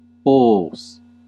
Ääntäminen
Synonyymit cornigera Ääntäminen Classical: IPA: /boːs/ Haettu sana löytyi näillä lähdekielillä: latina Käännös Ääninäyte Substantiivit 1. cow US 2. bull US 3. ox Suku: m .